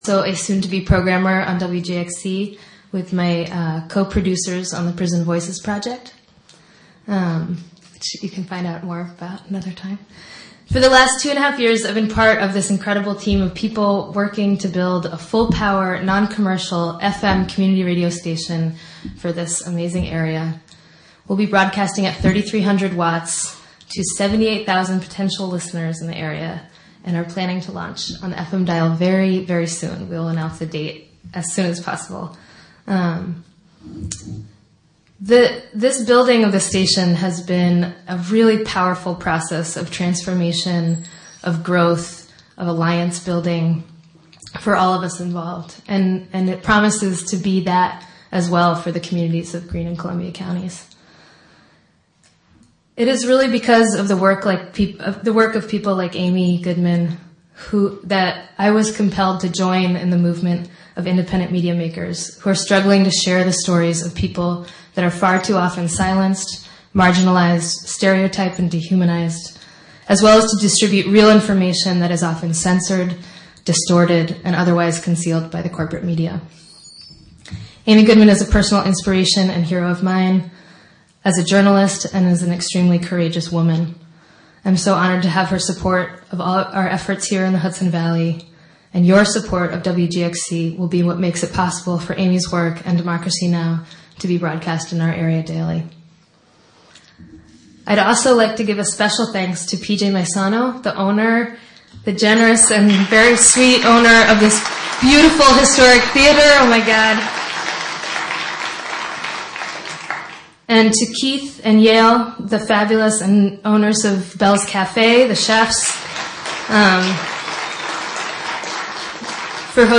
Wave Farm | Amy Goodman appearance for WGXC at Community Theatre of Catskill